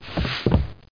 1 channel
woodroll.mp3